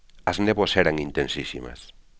as nÉBoas ÉraN inteNsísimas.